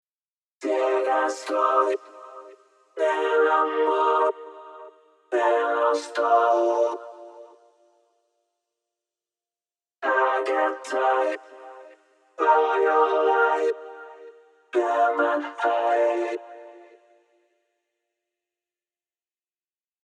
TRANSIENT CONTROL：トランジェントの調整（バックコーラスを想定しているため、アタックを弱めました）
TOOL：Widthを165%にしてステレオ感を広げた（Vocoderデバイスでステレオにしたが広がりが弱かったので）
バックトラックになじむようにディレイとリバーブを追加したのがこちら
Vocal_wet.mp3